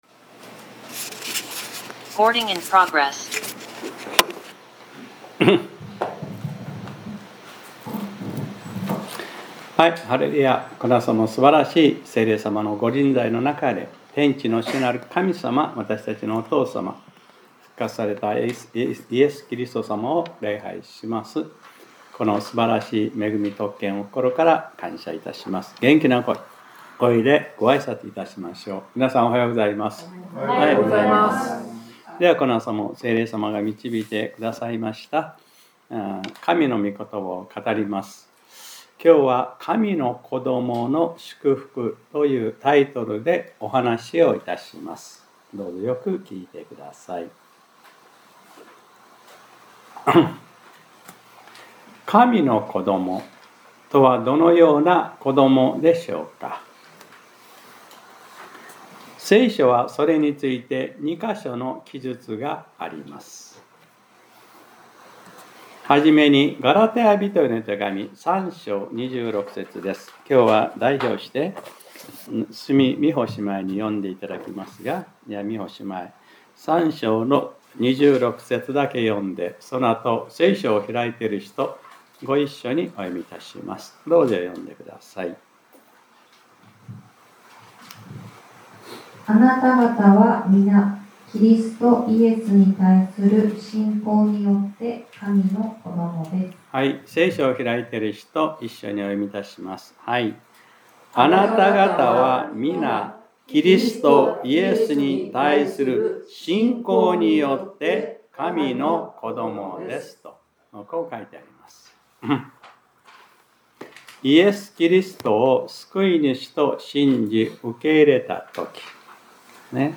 2026年02月22日（日）礼拝説教『 神の子の祝福 』 | クライストチャーチ久留米教会